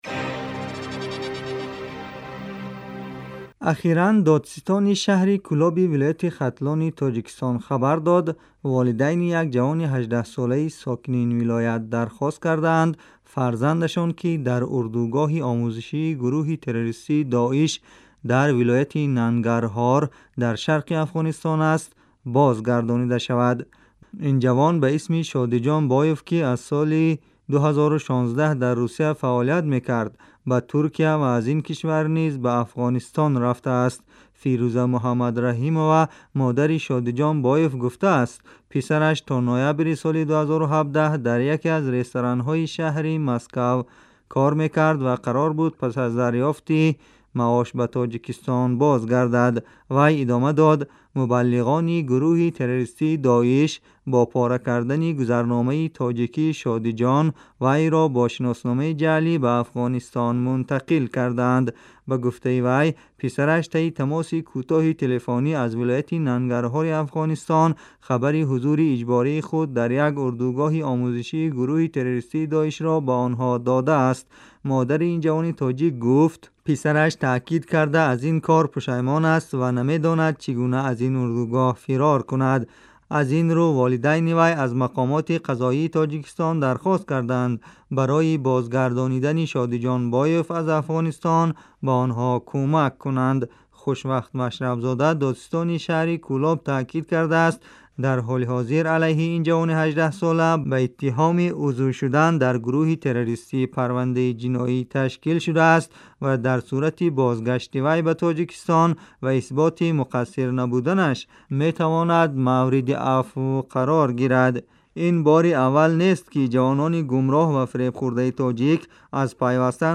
гузорише вижа